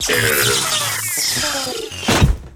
droid.ogg